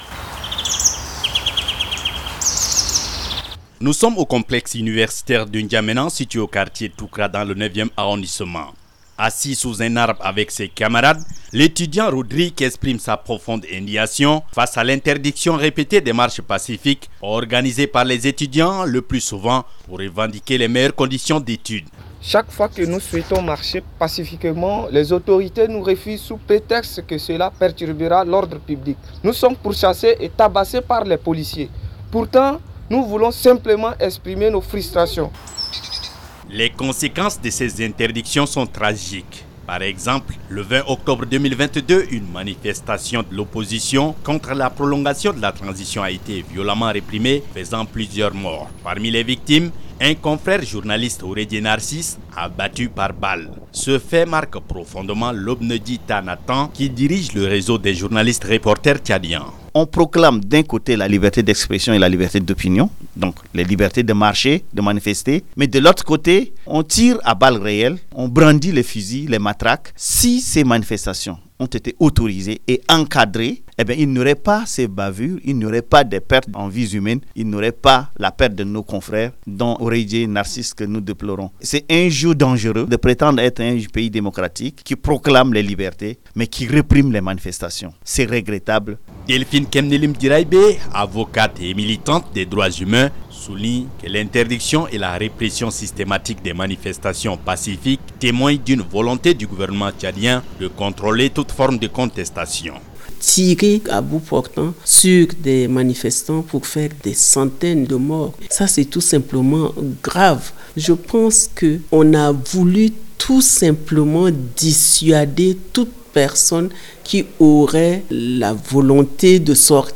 Reportage Radio – Droits et Libertés au Tchad : L’Interdiction des Marches Pacifiques.